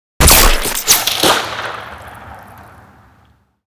Railgun_Near_02.ogg